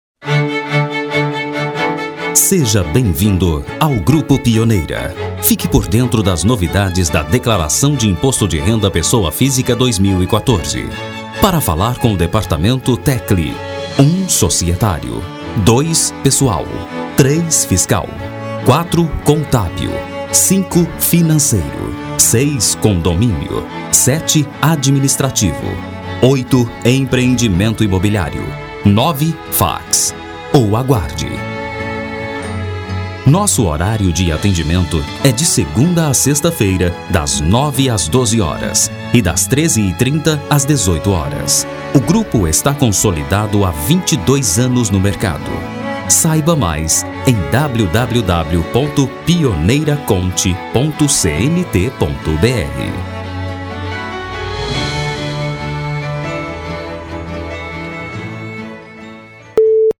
• espera telefonica